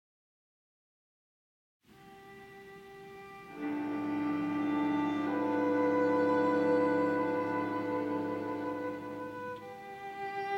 Deze drie tonen brengen mij daarmee in een heel andere wereld.
webern-op5-2-3tonen.mp3